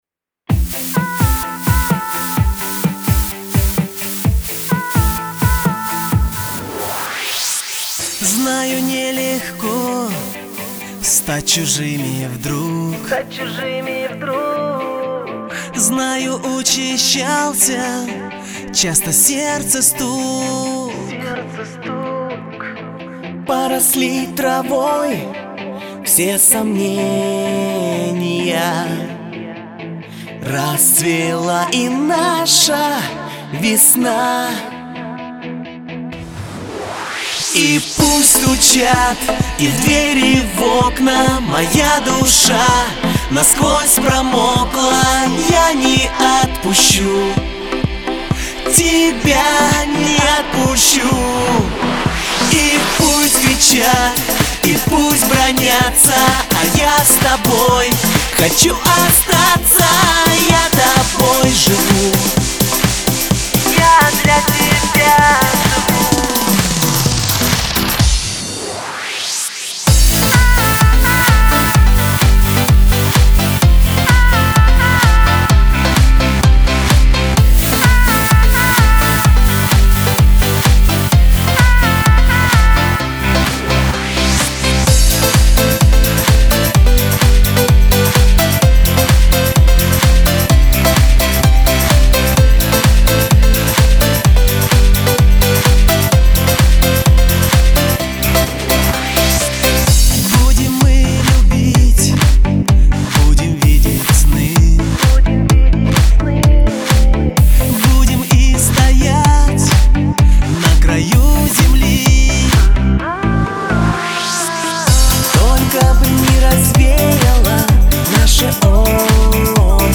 красивую и позитивную музыку